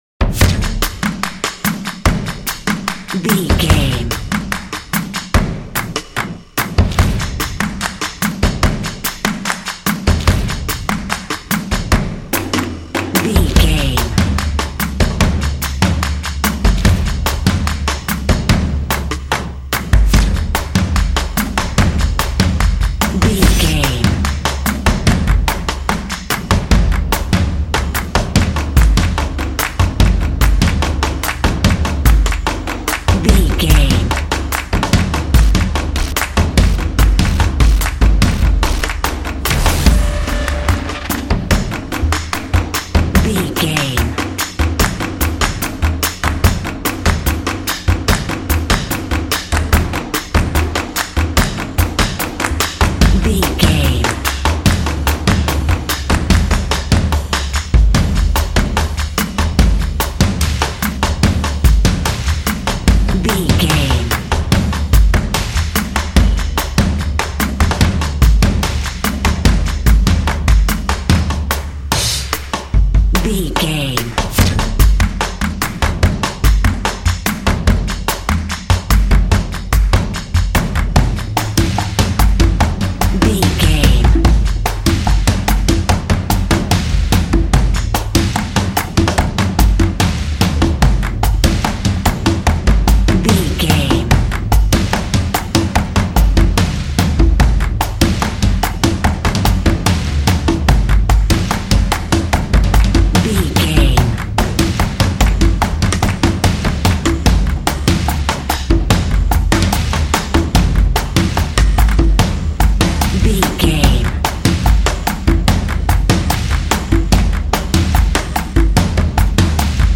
Epic / Action
Atonal
confident
tension
drums
drumline